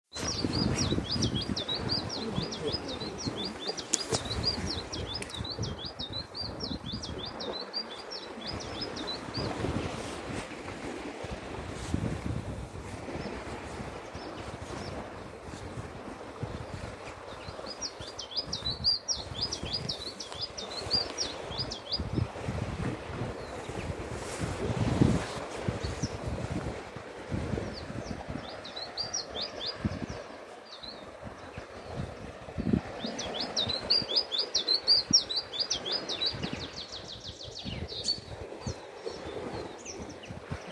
Siberian Chiffchaff, Phylloscopus collybita tristis
Administratīvā teritorijaDundagas novads
StatusVoice, calls heard